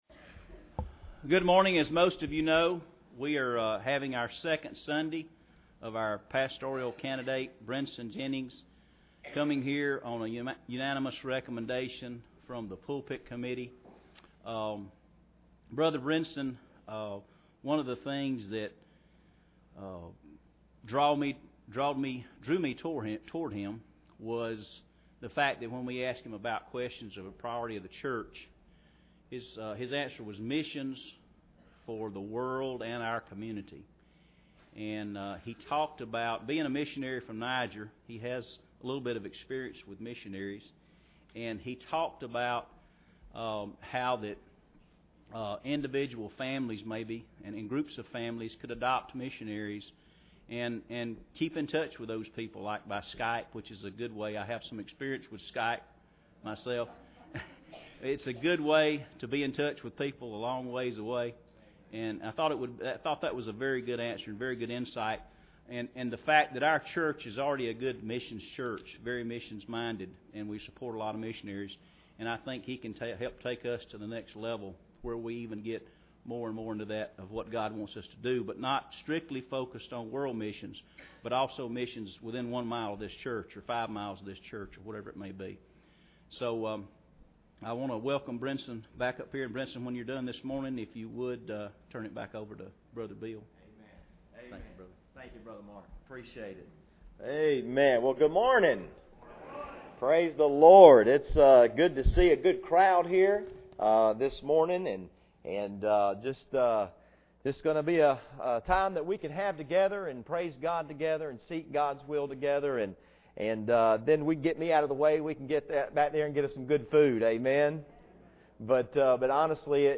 Esther 4:1-4 Service Type: Sunday Morning Bible Text